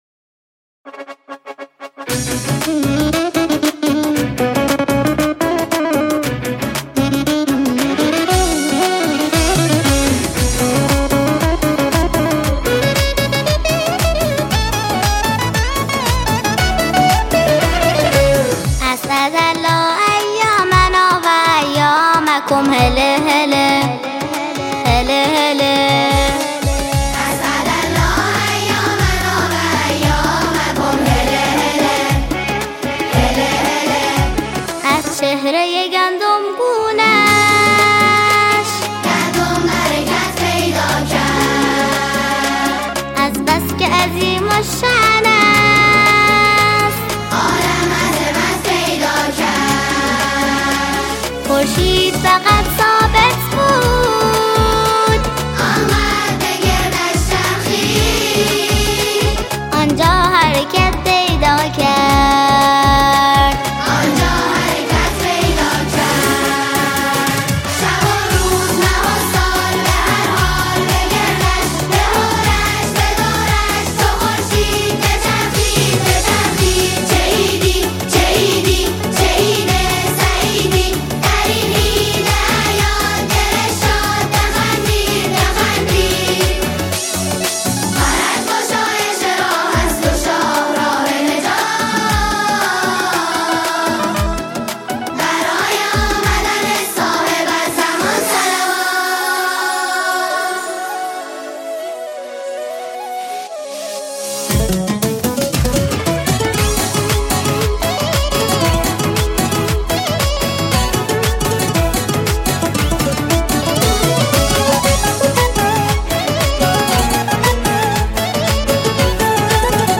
سرود ، سرود کودک و نوجوان ، سرود مذهبی ، سرود مناسبتی